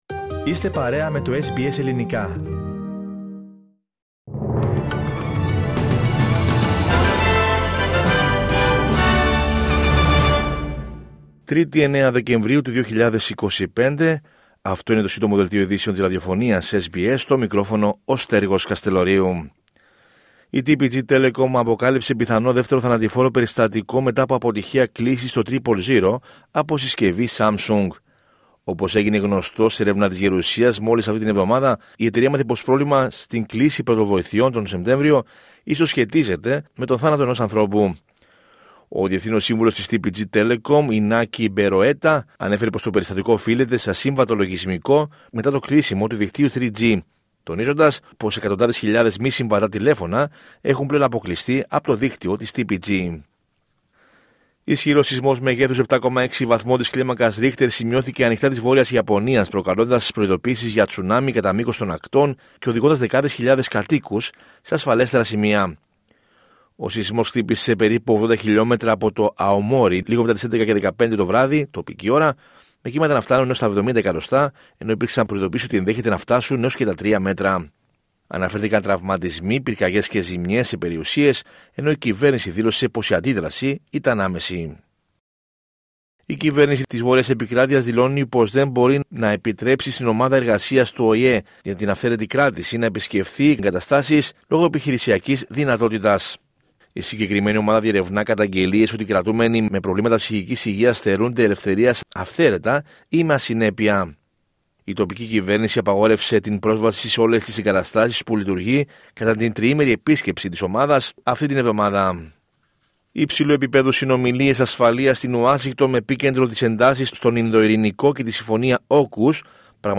H επικαιρότητα έως αυτή την ώρα στην Αυστραλία, την Ελλάδα, την Κύπρο και τον κόσμο στο Σύντομο Δελτίο Ειδήσεων της Τρίτης 9 Δεκεμβρίου 2025.